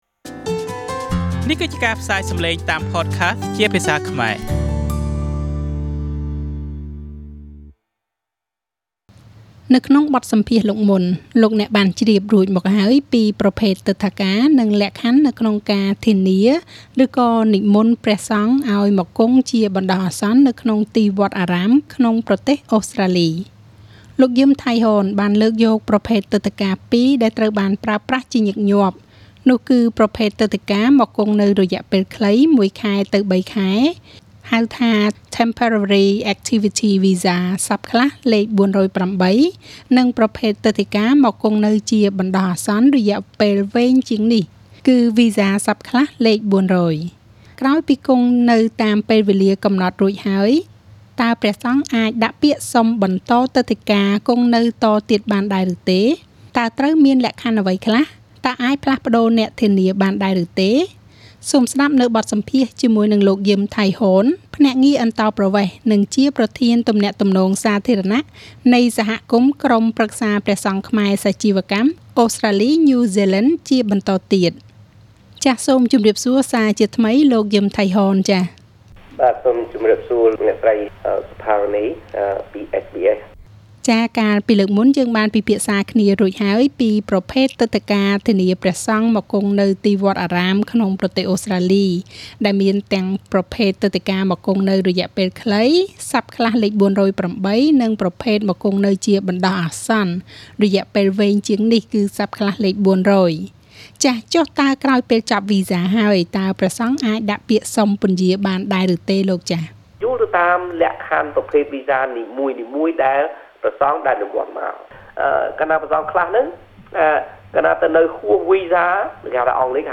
SBS ខ្មែរ